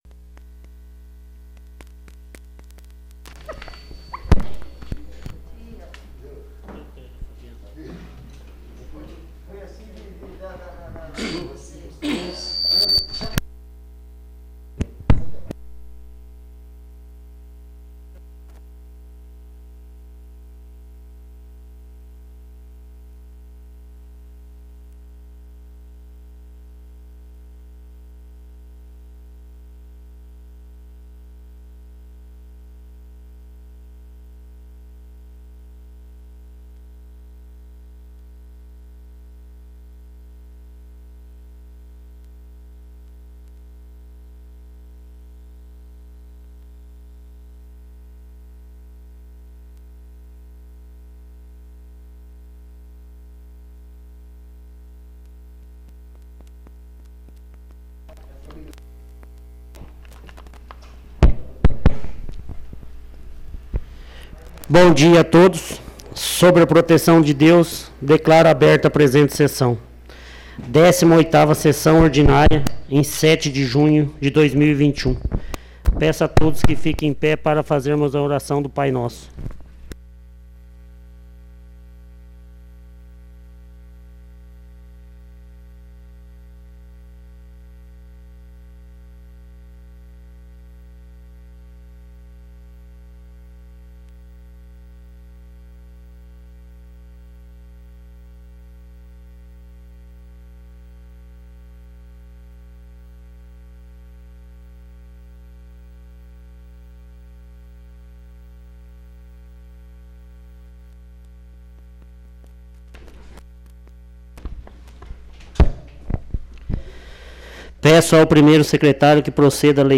18ª Sessão Ordinária